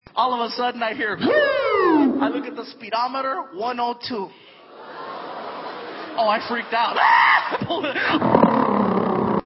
siren/ laugh
Category: Comedians   Right: Both Personal and Commercial